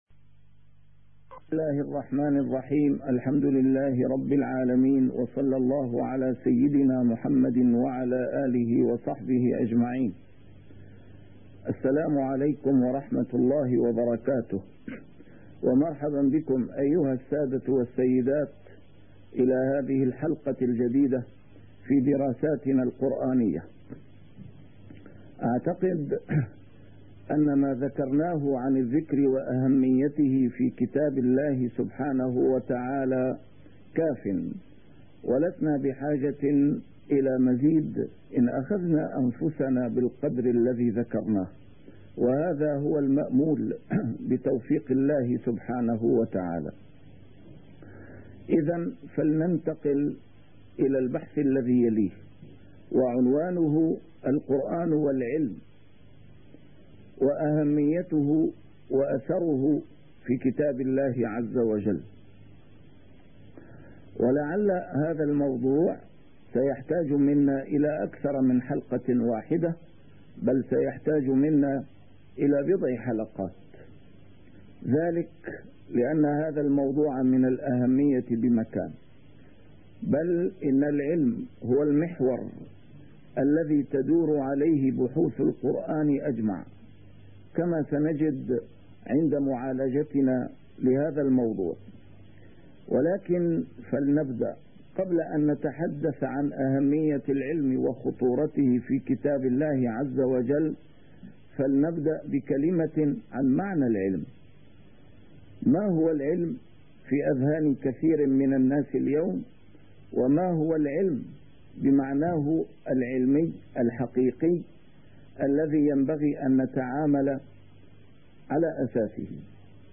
A MARTYR SCHOLAR: IMAM MUHAMMAD SAEED RAMADAN AL-BOUTI - الدروس العلمية - دراسات قرآنية - القرآن والعلم وأهميته وأثره في كتاب الله عز وجل